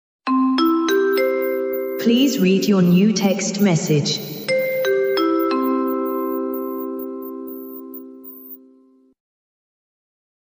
Genre: Nada notifikasi
Suaranya keren, lucu, dan auto bikin temen penasaran.